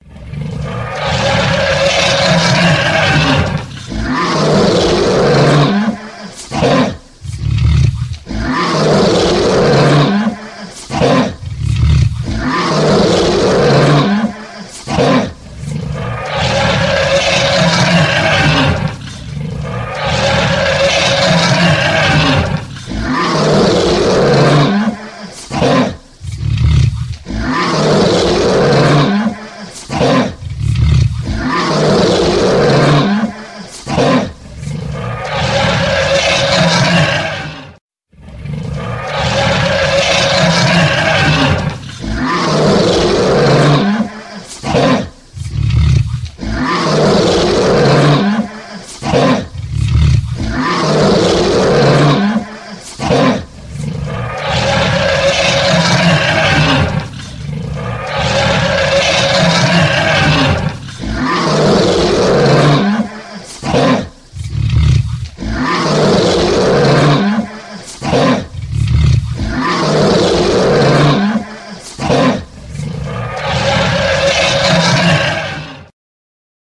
Tiếng gầm của Sư Tử
Tiếng động vật 314 lượt xem 10/03/2026
Download tiếng gầm của sư tử mp3, tải file hiệu ứng âm thanh tiếng gầm rú của động vật hoang dã, tải miễn phí tiếng sư tử gầm thể hiện quyền uy chất lượng cao...